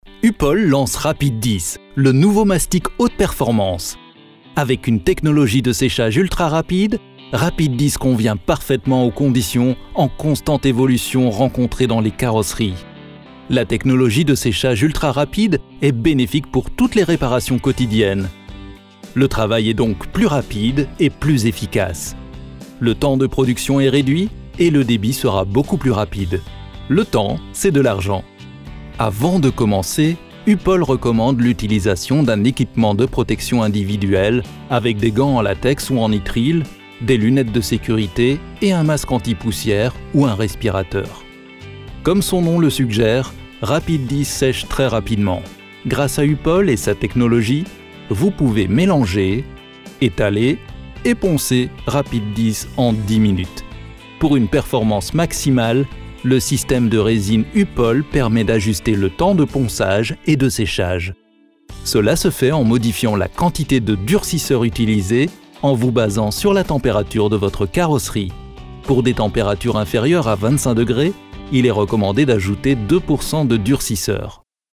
présentation produit (web)
Étiquettes confiant , droit , informatif